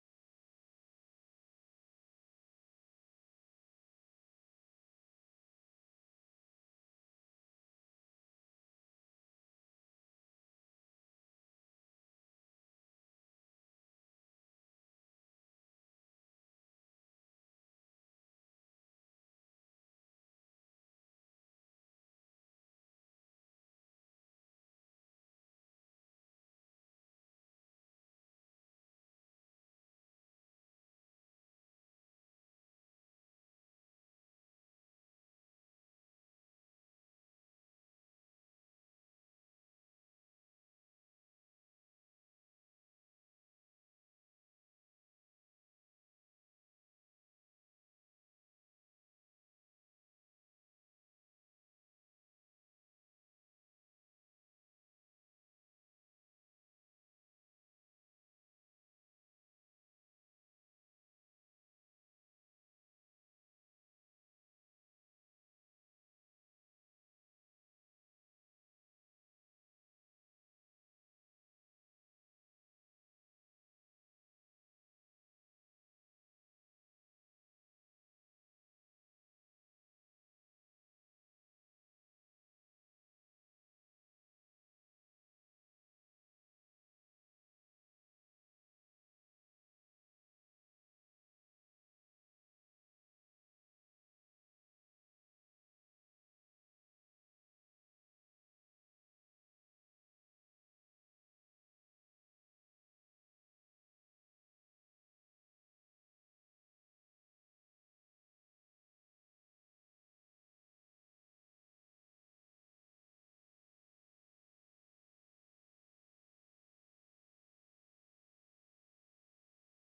City of Powder Springs Town Hall Meeting 11/18/2025 on 18-Nov-25-23:56:12